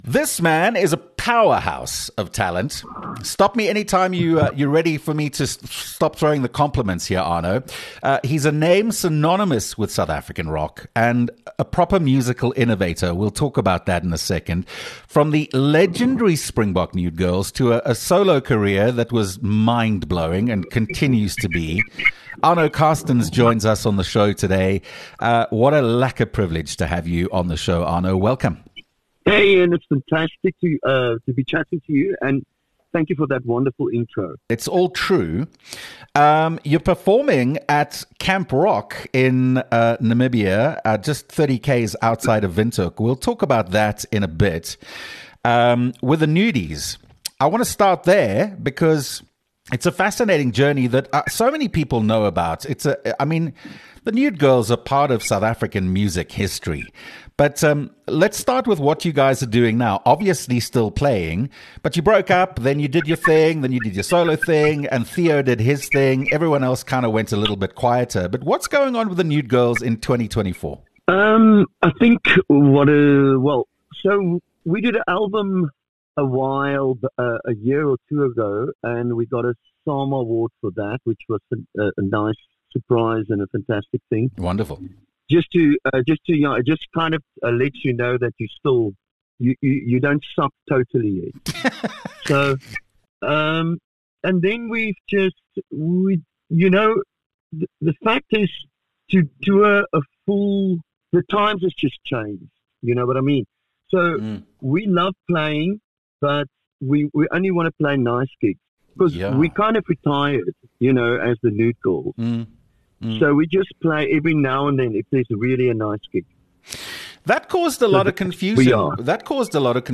An interview with Arno Carstens talking about The Springbok Nude Girls, his solo career and his art.